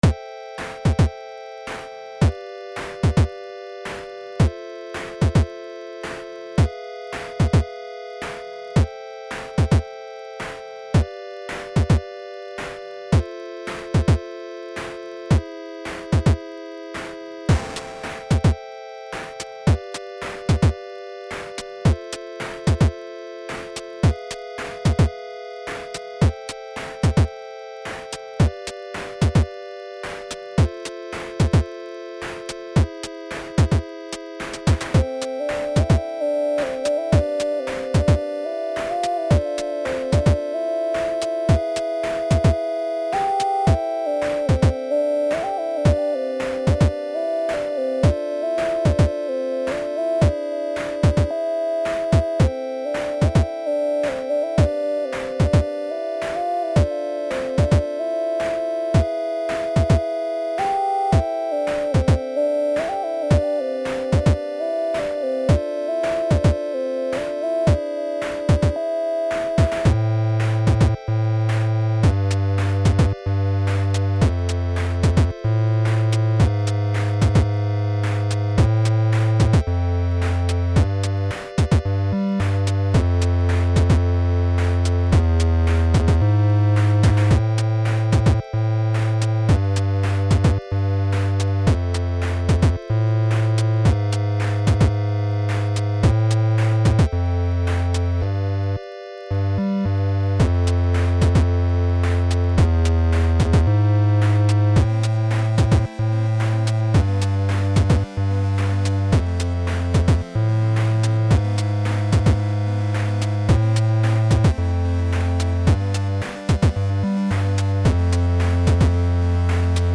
Chiptune Factory
chiptune_factory_0.mp3